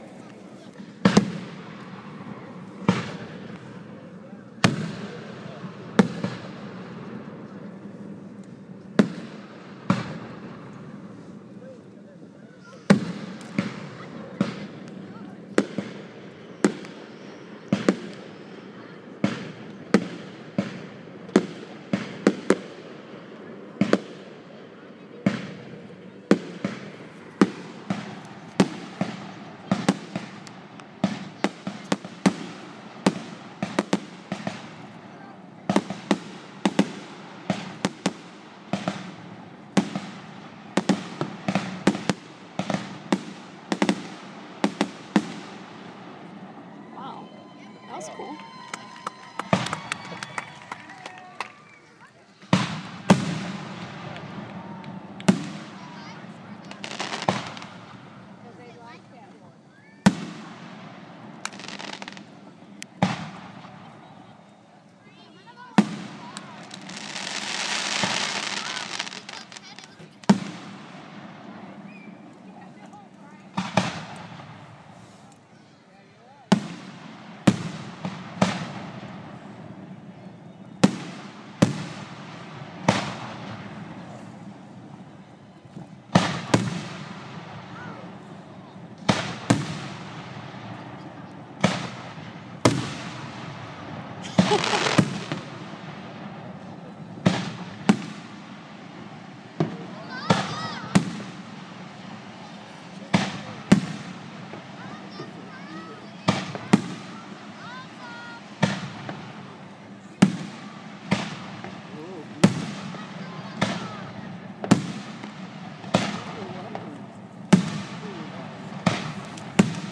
Happy Independence Day fireworks show for those who couldn't attend one. :-) part 2